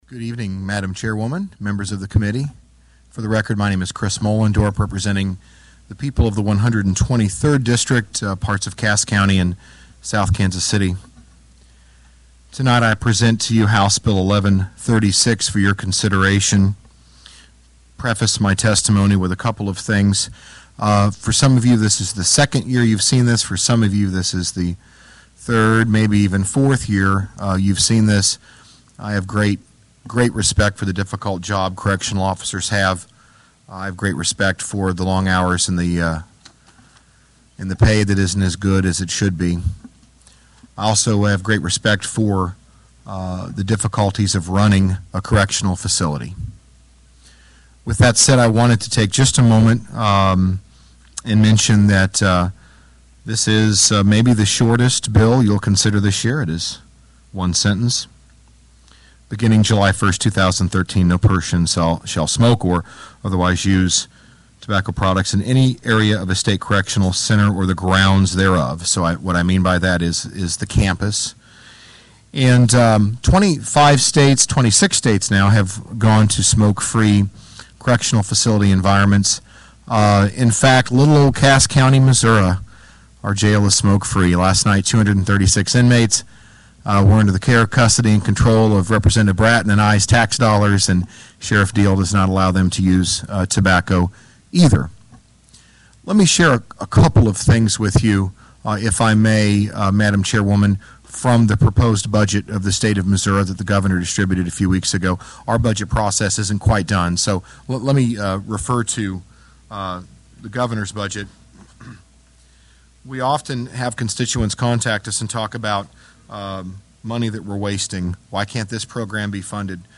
House-Cmte-hearing-on-HB-1136.mp3